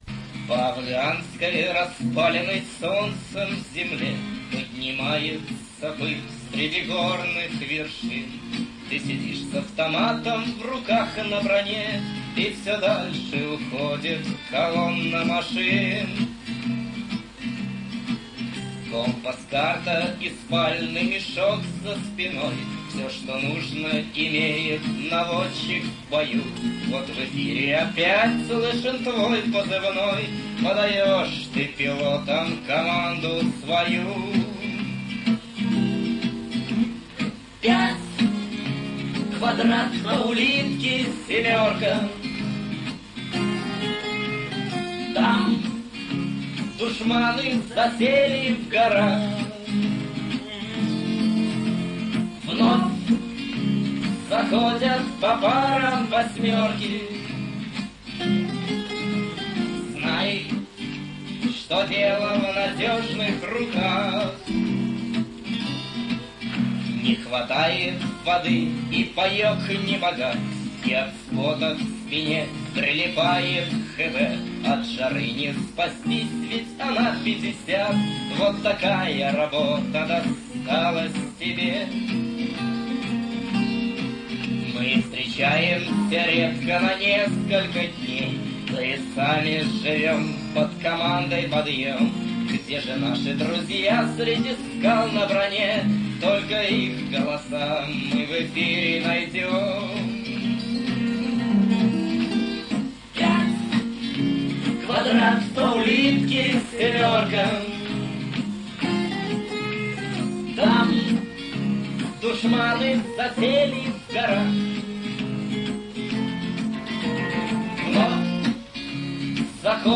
Оцифровка с "афганской" кассеты.